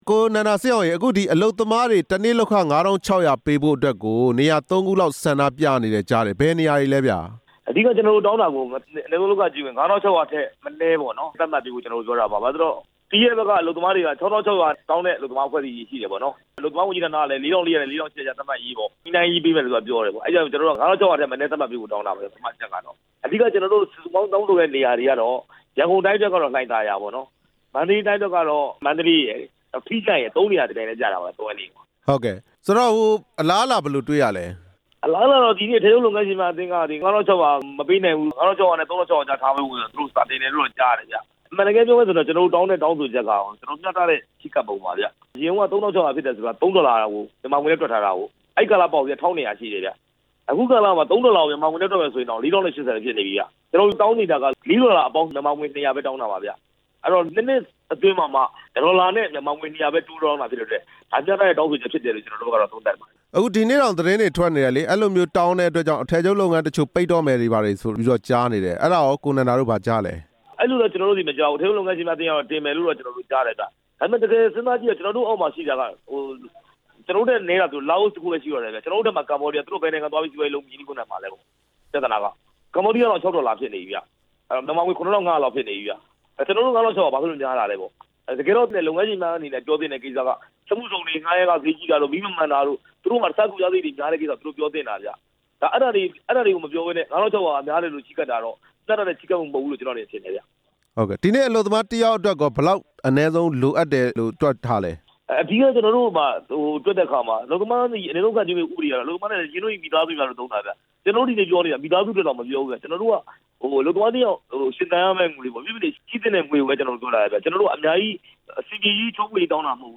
တနေ့ လုပ်ခလစာ ၅၆၀၀ သတ်မှတ်ပေးဖို့ အကြောင်းမေးမြန်းချက်